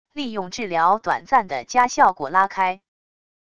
利用治疗短暂的加效果拉开wav音频